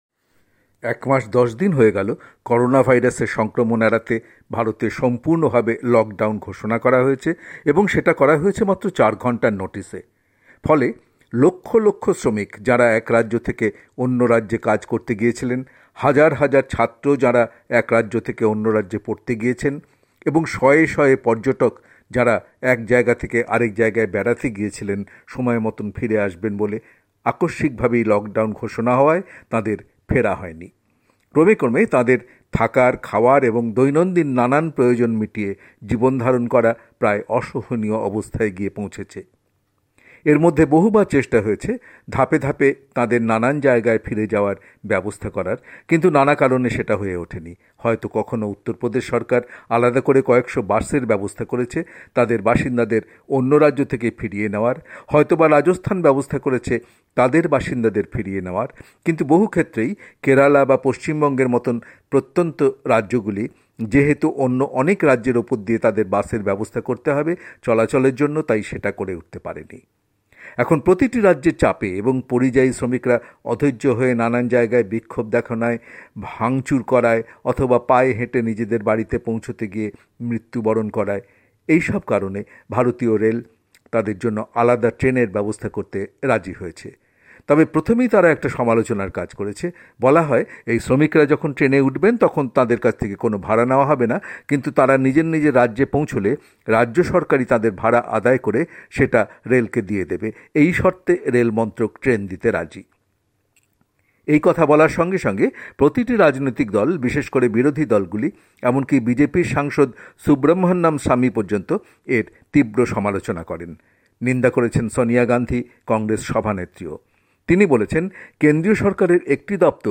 কলকাতা থেকে
রিপোর্ট।